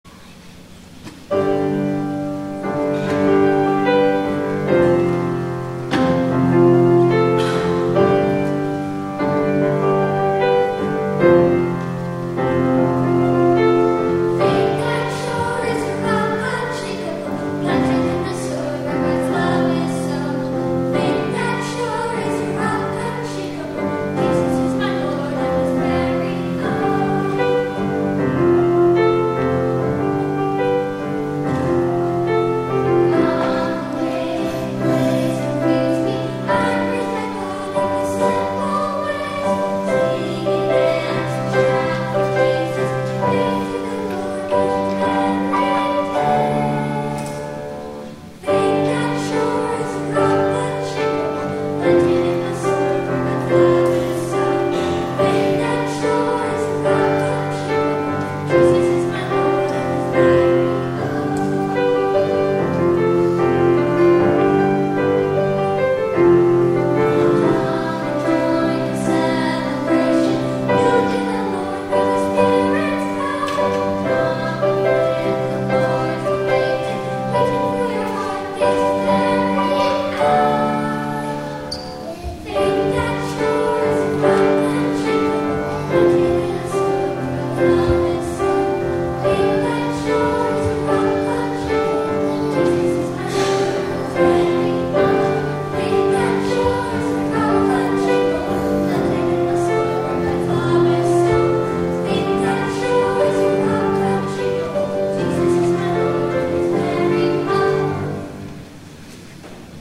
THE INTROIT
Faith That’s Sure (Treble Choir)  Suzanne Lord